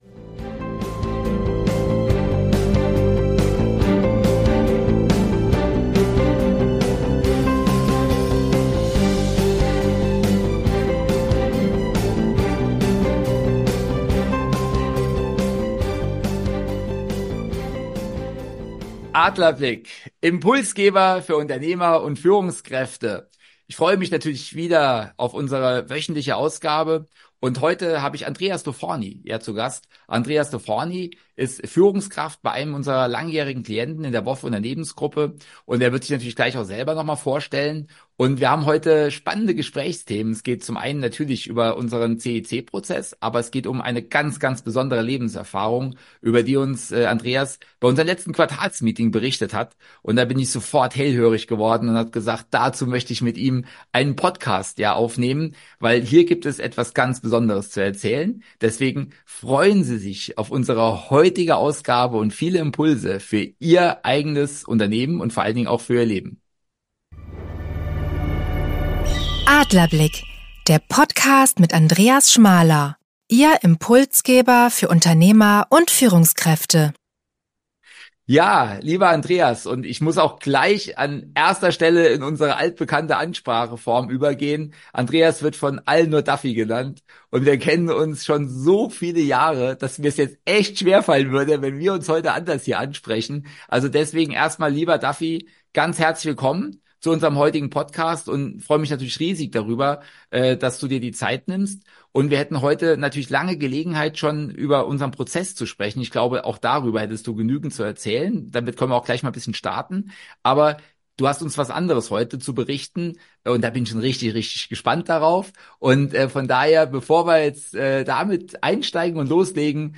In einem spannenden Gespräch